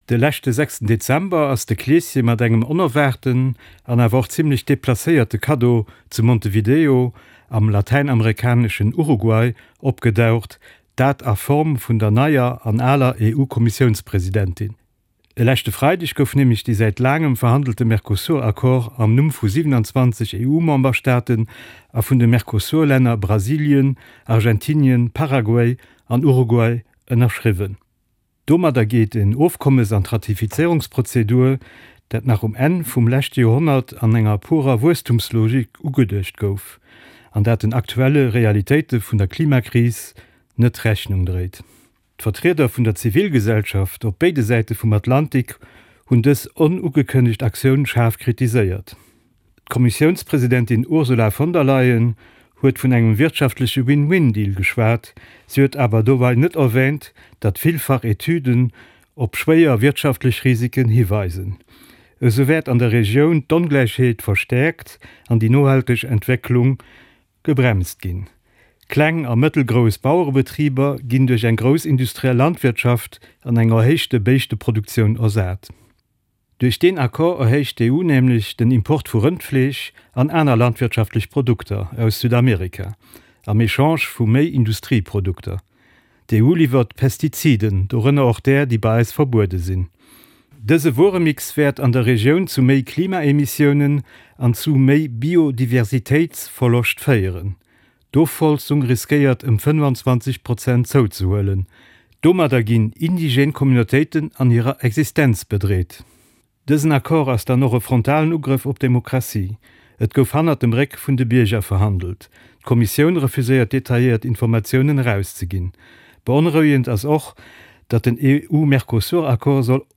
Commentaire Carte Blanche RTL Luxembourg RTL Radio Lëtzebuerg News Luxembourg RTL